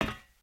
sounds / material / human / step / metal_plate3.ogg
metal_plate3.ogg